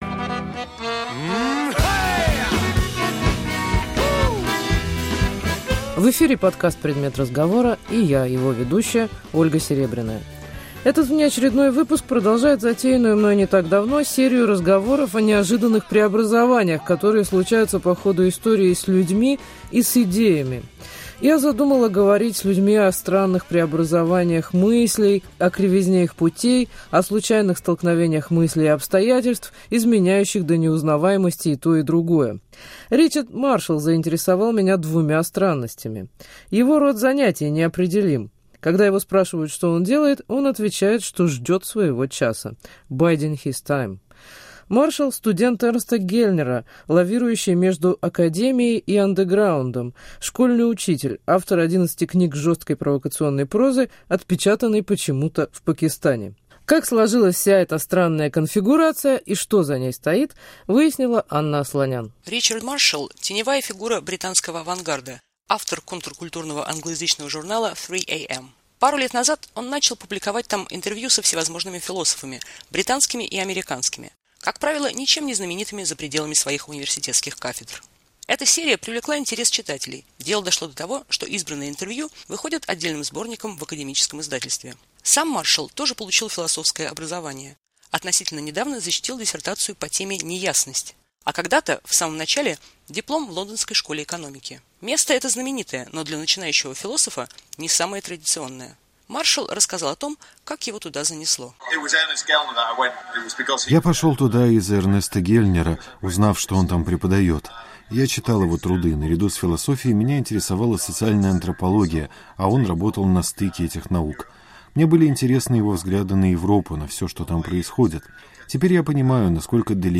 "Предмет разговора": интервью